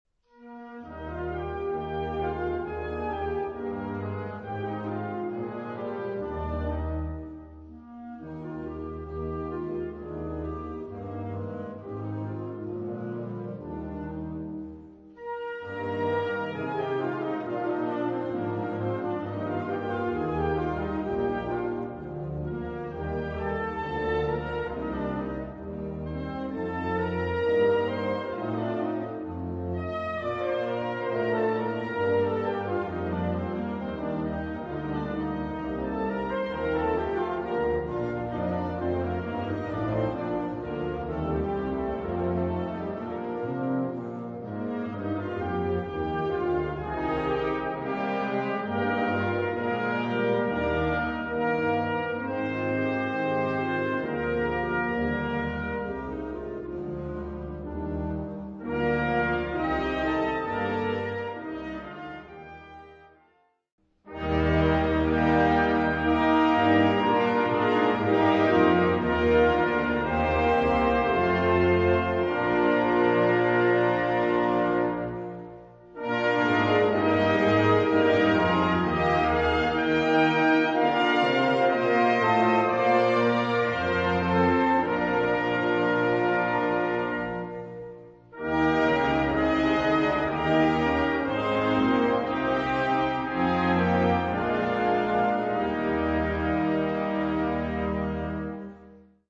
Gattung: Choräle für Blasorchester
Besetzung: Blasorchester